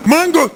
hitsound_retro2.wav